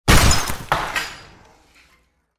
Звуки выбитой двери
Выбивание двери – Вариант 2, немного громче